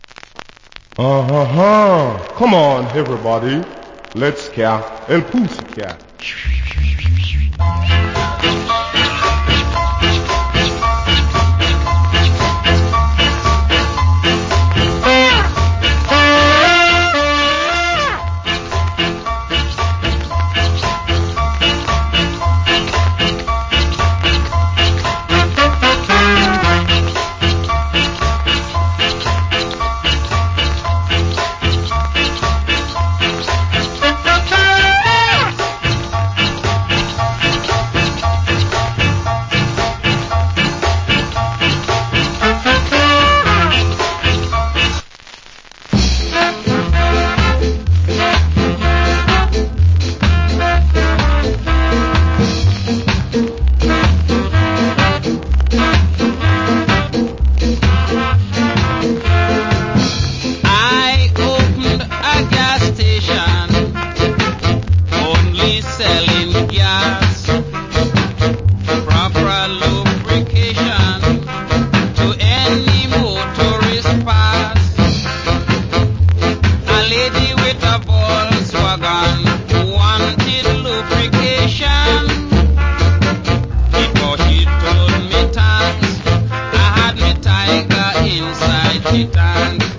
Great Ska Inst. / Wicked Ska Vocal.